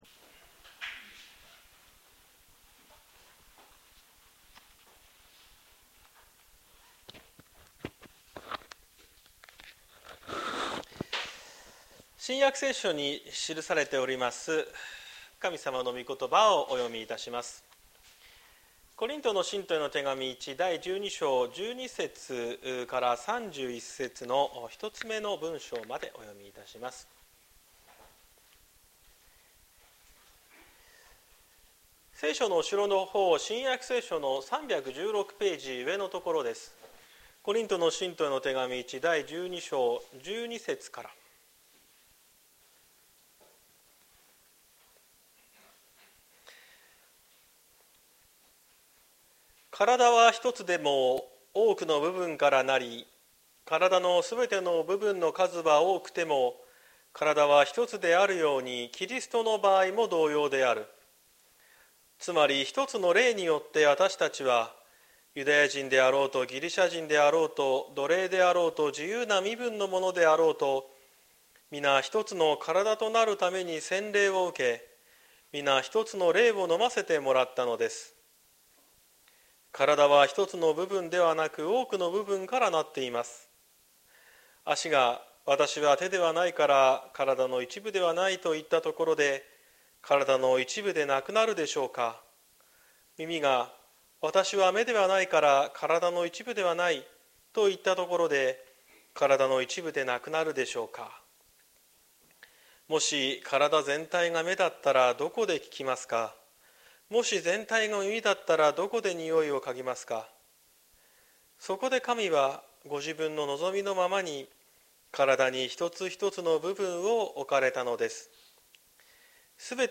2024年11月24日朝の礼拝「キリストの教会を整える」綱島教会
説教アーカイブ。